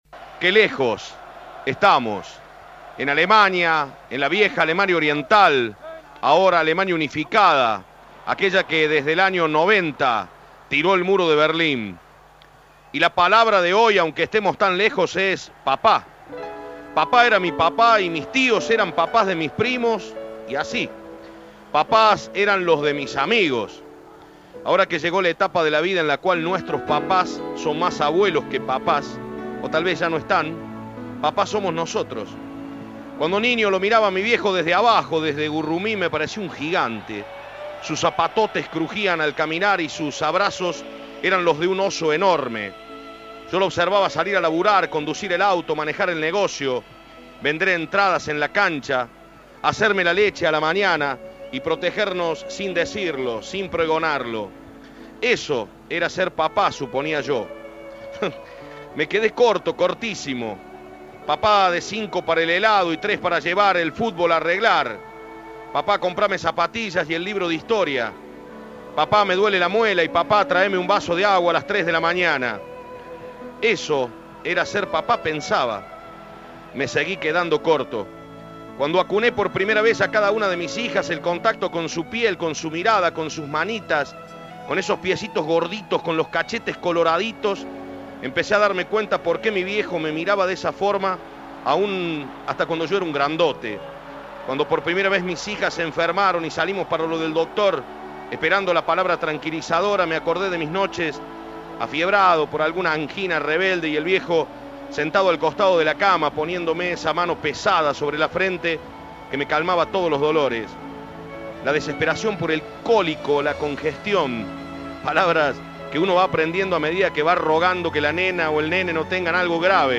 El recuerdo del relator y periodista, con un audio que eriza la piel. "En la vida somos nosotros mismos, los que de un momento para el otro, dejamos de ser el nene para ser papá", susurró.